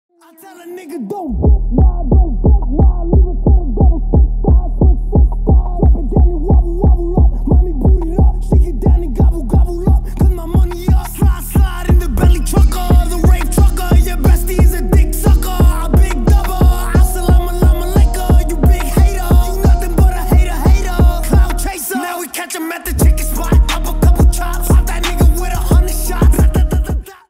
Ремикс
Поп Музыка